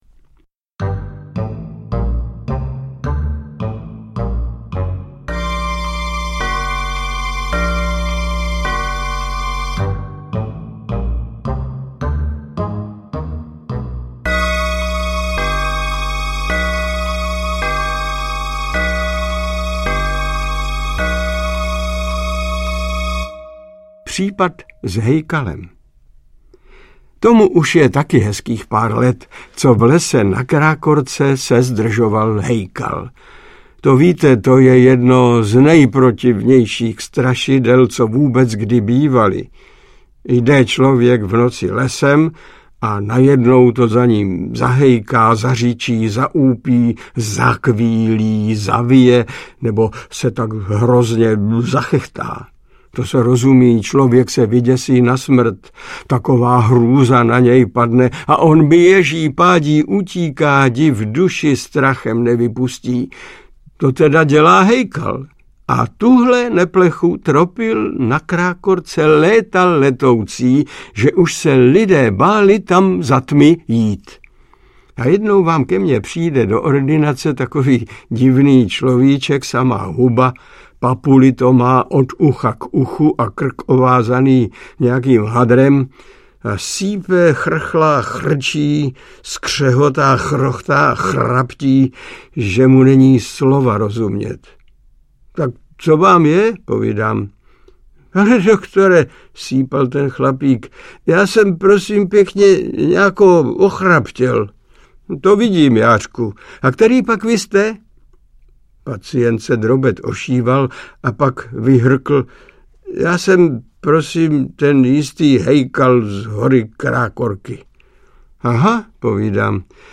Audio kniha
Ukázka z knihy
• InterpretMiloň Čepelka